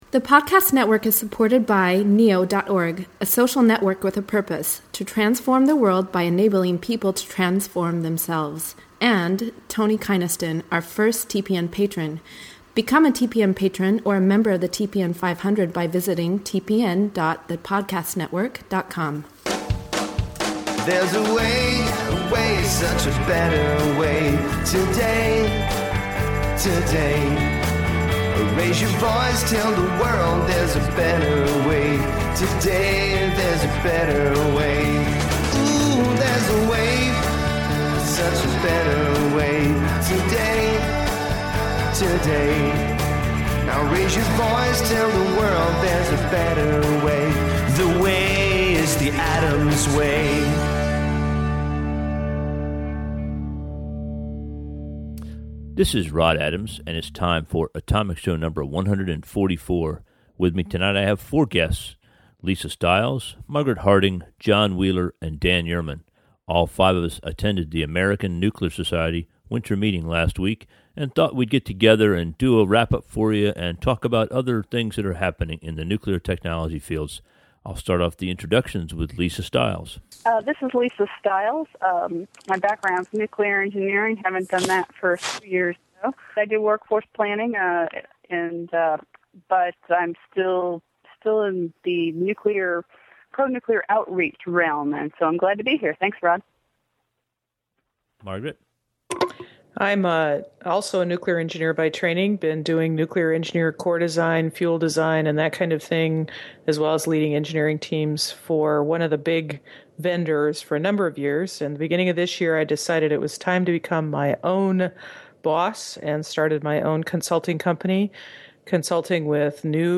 On November 22, 2009, flush with good feelings about the recent American Nuclear Society (ANS) Winter Meeting, I invited four others who attended the meeting to get together for a conference call to talk about what we heard and learned.